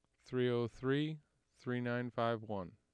6.  Spectrogram and AIF tracks for speech utterance “